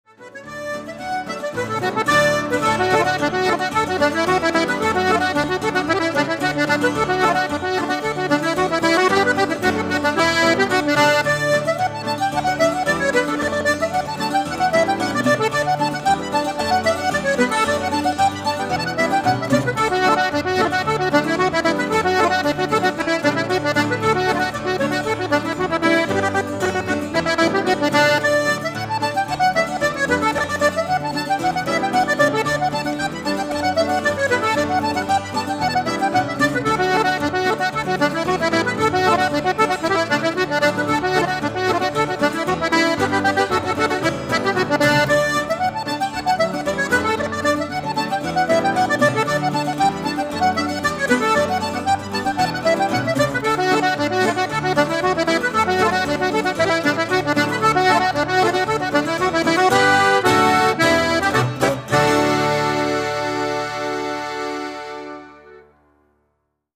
Irish Reel ... The Dairymaid
A classic reel, it was first recorded by James Morrison.